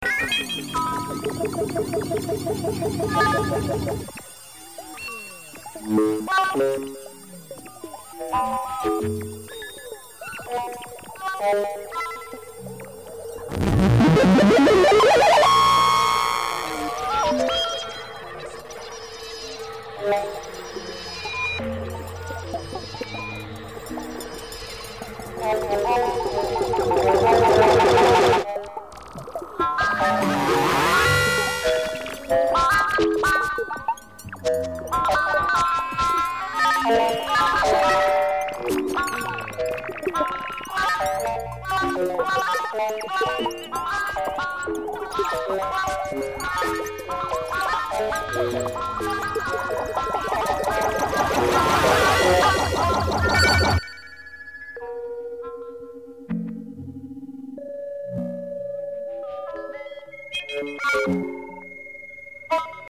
electronics
electronic two-channel composition